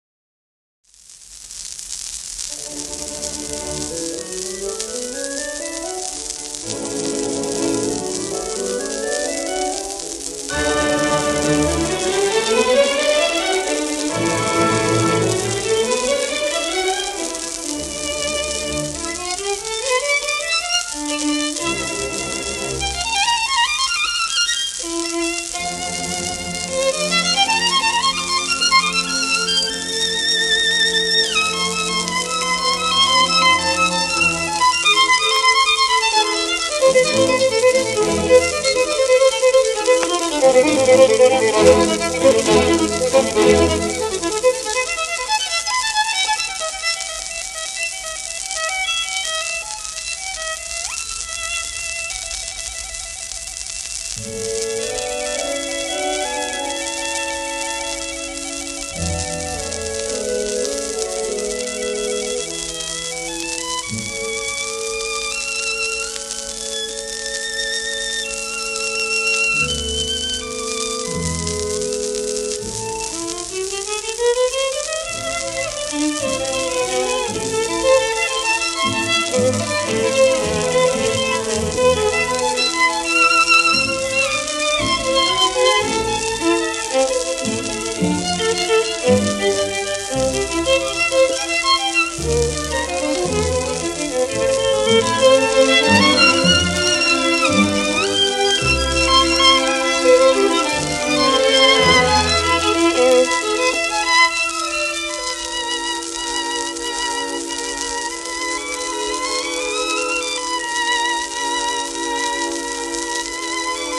1932年録音